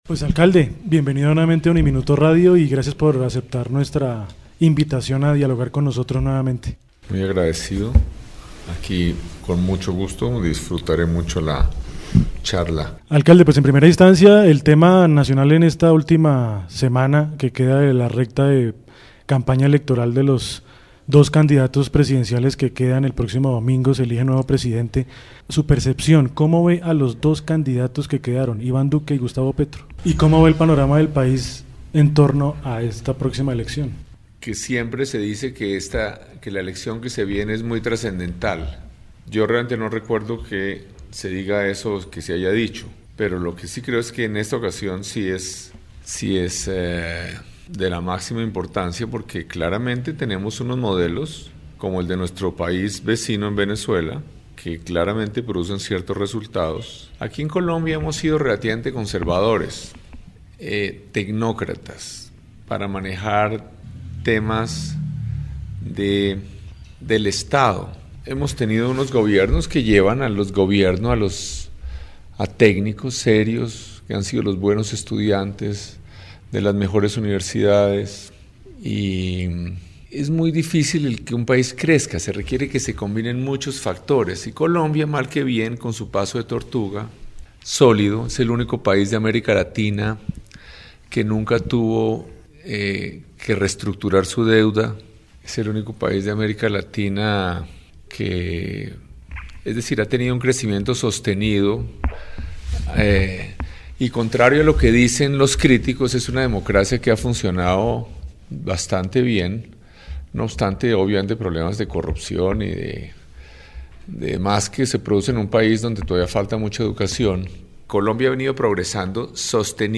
En diálogo exclusivo de UNIMINUTO Radio con el alcalde de Bogotá Enrique Peñalosa habló sobre qué pasará con los proyectos que tiene Bogotá y el nuevo presidente que se elija el próximo domingo entre Gustavo Petro e Iván Duque.
Entrevista-alcalde-de-Bogotá-Enrique-Peñalosa.mp3